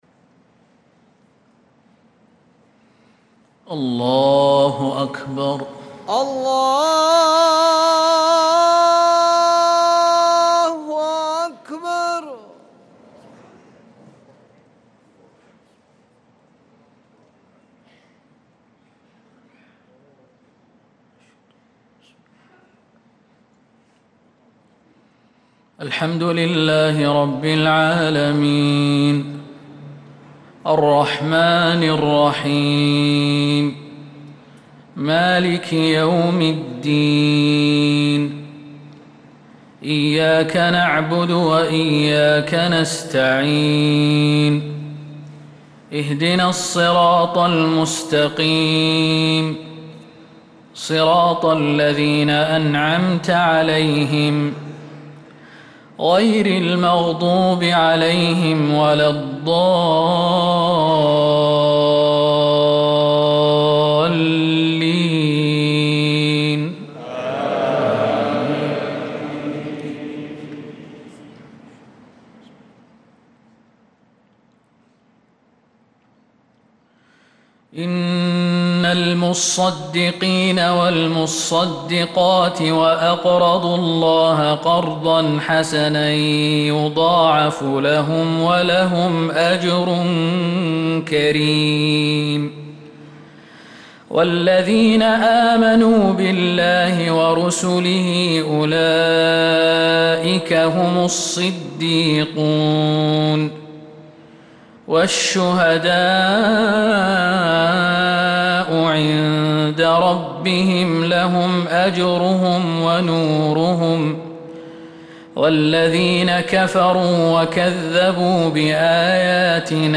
صلاة الفجر للقارئ خالد المهنا 6 ذو الحجة 1445 هـ
تِلَاوَات الْحَرَمَيْن .